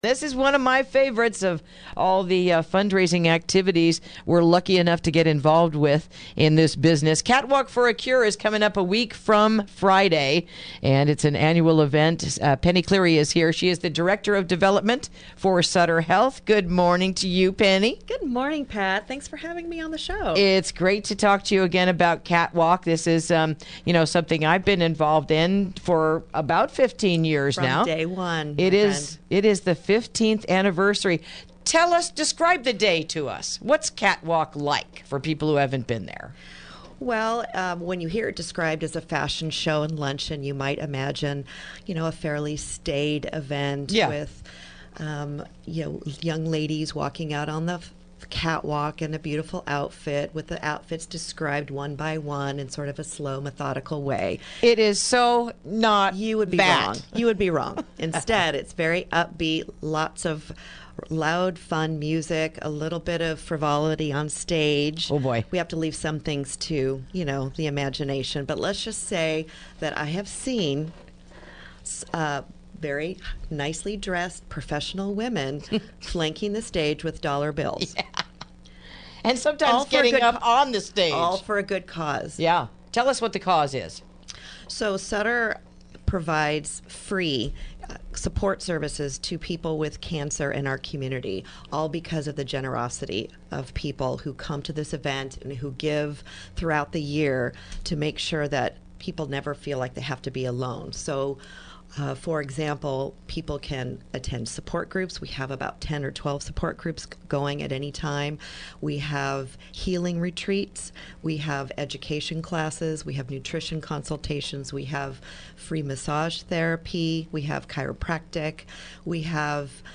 Interview: Catwalk for a Cure